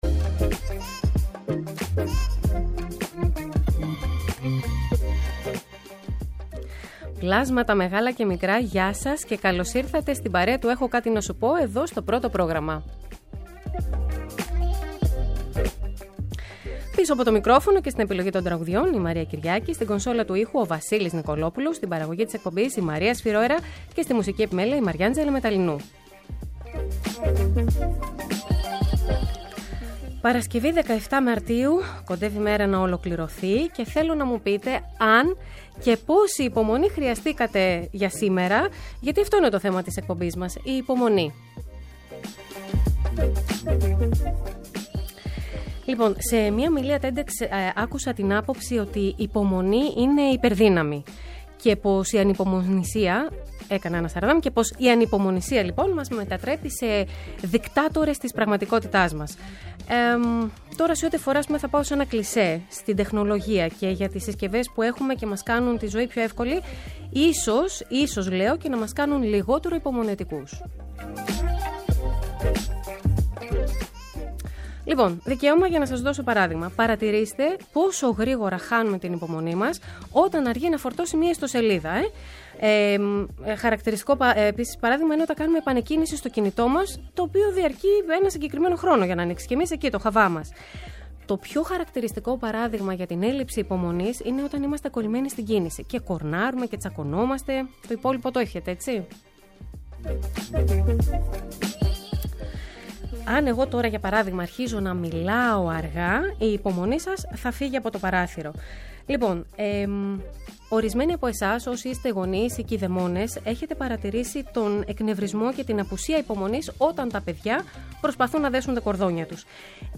Ένα τραγούδι γίνεται οδηγός για το κεντρικό θέμα σε κάθε εκπομπή. Για το ευ στο ζην, από συναισθήματα και εμπειρίες μέχρι πεποιθήσεις που μας κάνουν να δυσλειτουργούμε ή να κινητοποιούμαστε, έχουν κάτι να μας πουν ειδικοί σε επικοινωνία με ακροατές.